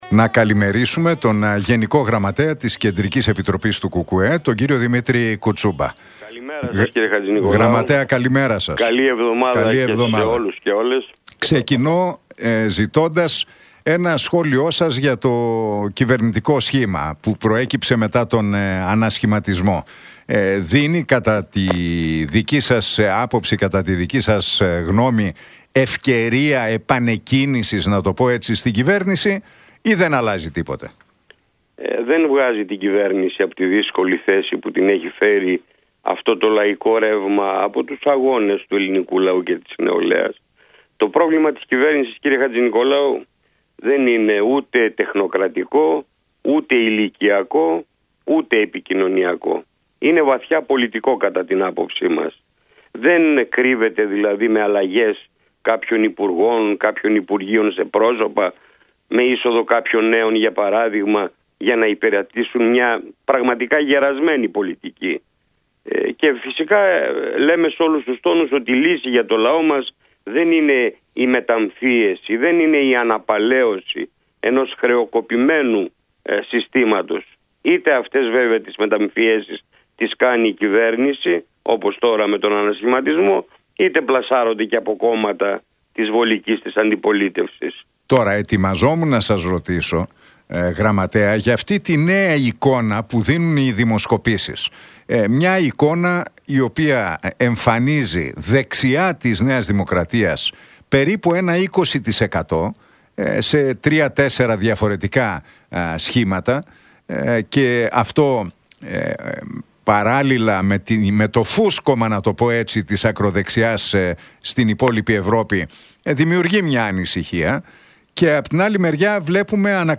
Λύση για τον λαό δεν είναι η αναπαλαίωση του αστικού πολιτικού συστήματος, είτε αυτό αφορά τους κυβερνητικούς ανασχηματισμούς είτε τις προσπάθειες των κομμάτων της βολικής αντιπολίτευσης, τόνισε ο γενικός γραμματέας της Κεντρική Επιτροπής του ΚΚΕ, Δημήτρης Κουτσούμπας, σε συνέντευξη εφ’ όλης της ύλης που παραχώρησε στον Νίκο Χατζηνικολάου και τον Realfm 97,8.